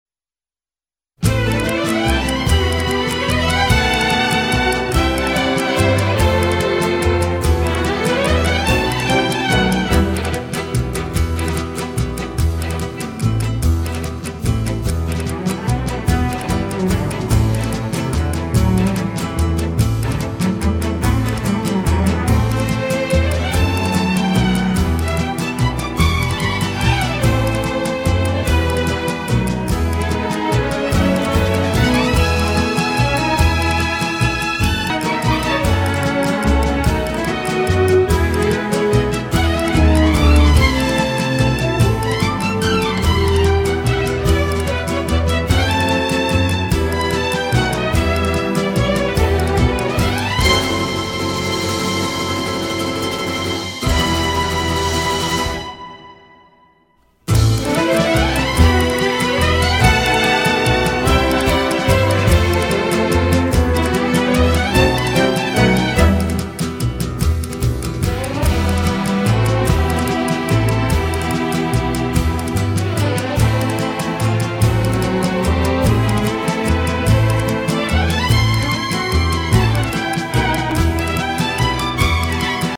• Теги: минусовка